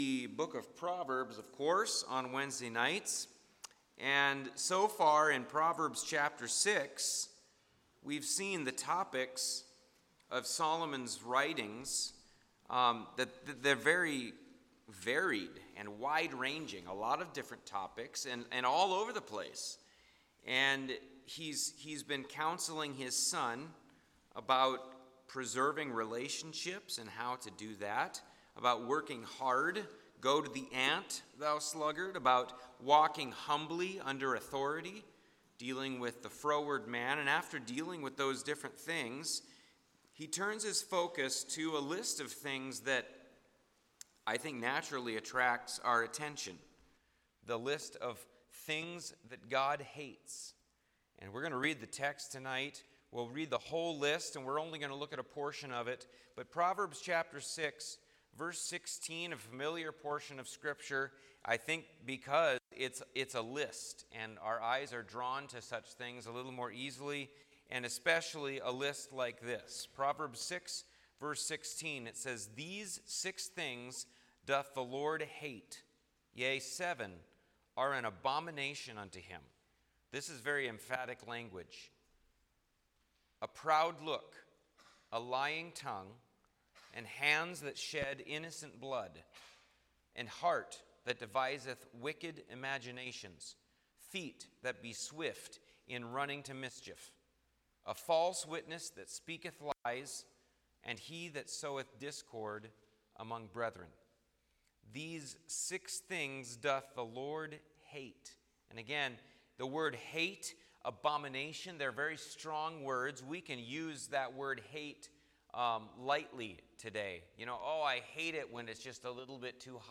Sermons (audio) - Bible Baptist Church